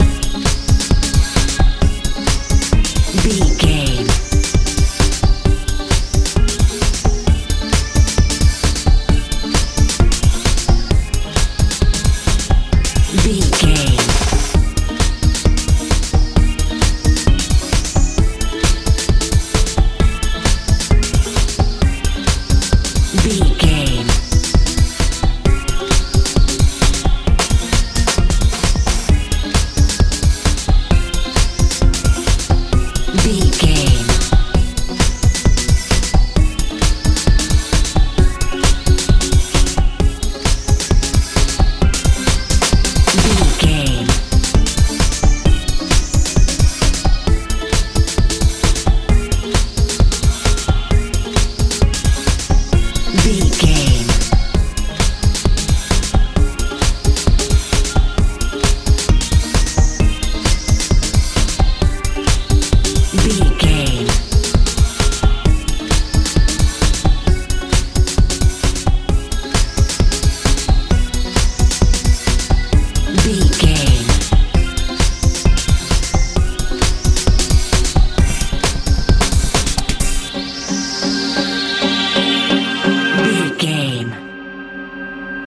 k pop feel
Ionian/Major
C♯
magical
mystical
synthesiser
bass guitar
drums
suspense